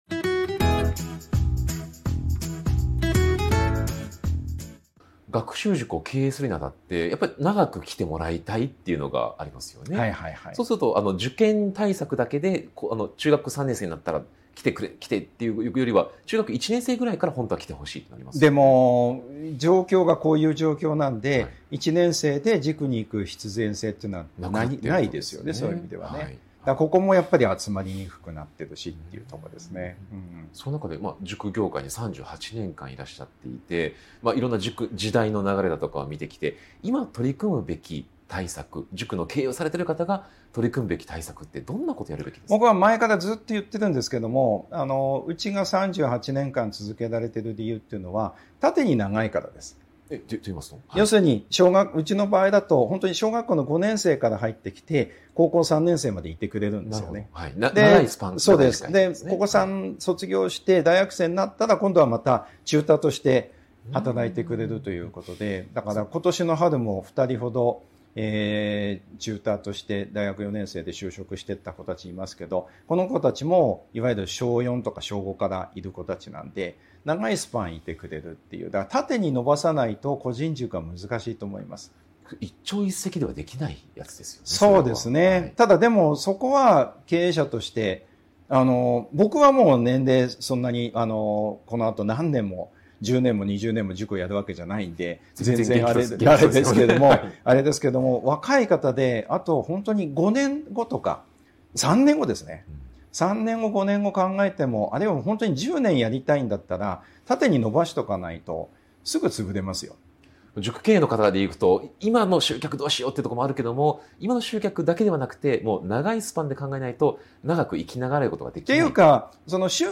【特別インタビュー】塾業界38年の現場から見える未来の学習塾経営とは